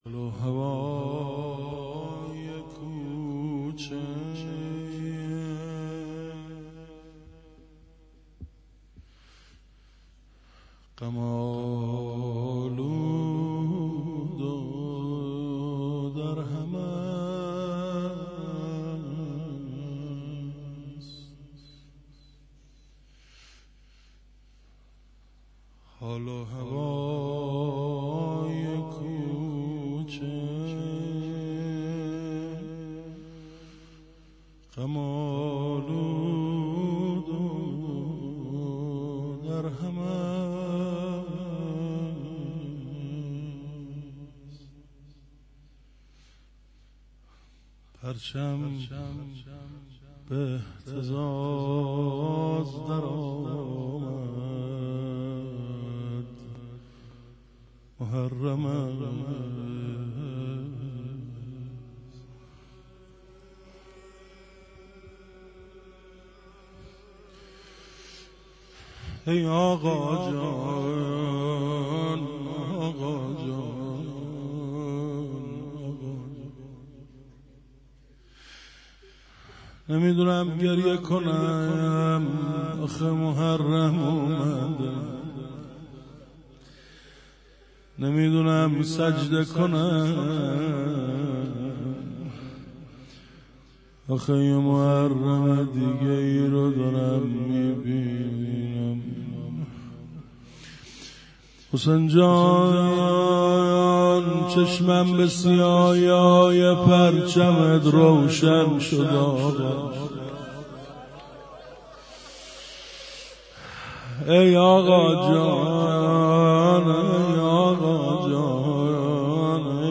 1- روضه
شور